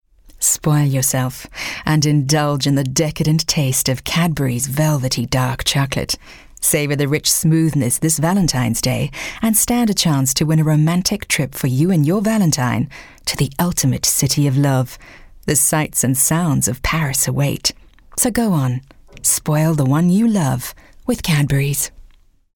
English Sexy